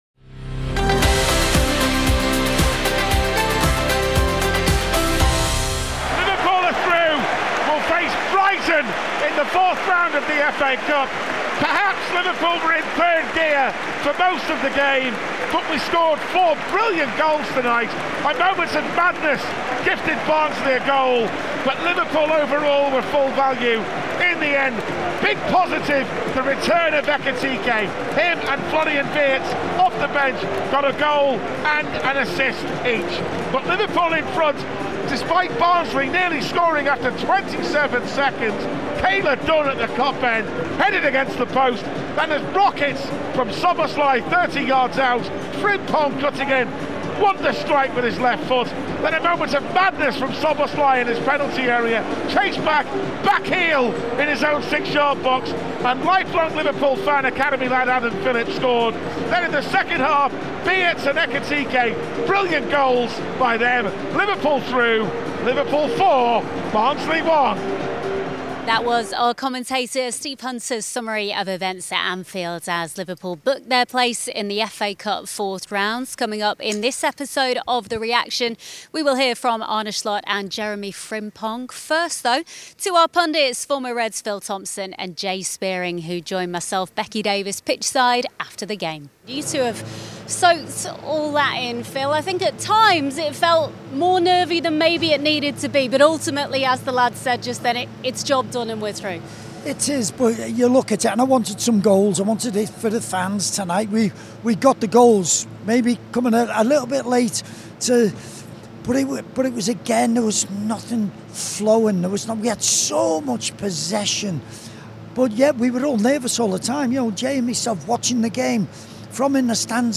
Arne Slot and Jeremie Frimpong reflect on Liverpool’s 4-1 victory over Barnsley in the FA Cup, after goals from Dominik Szoboszlai, Frimpong, Florian Wirtz and Hugo Ekitike set up a fourth-round tie against Brighton.
Former Reds Phil Thompson and Jay Spearing also provide pitchside analysis at Anfield.